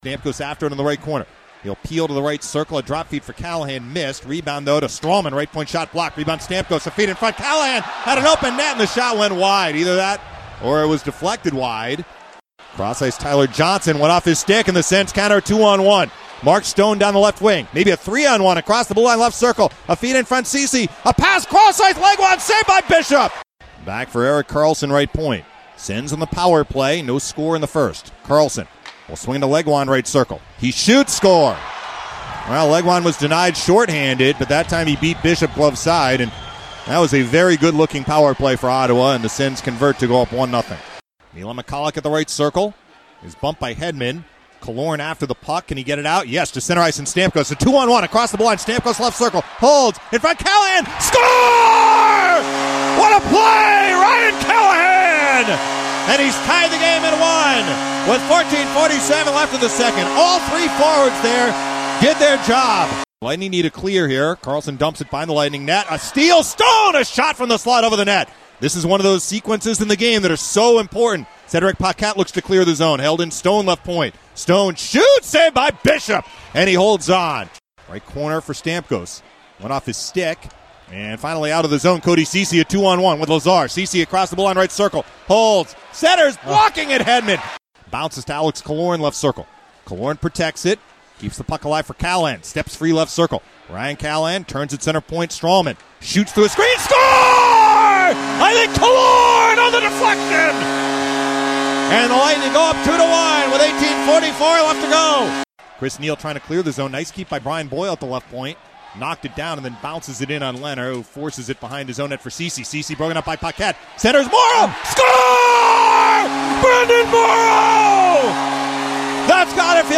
Game Highlights from the 11-29-14 match vs. Ottawa Senators.